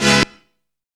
SEEDY STAB.wav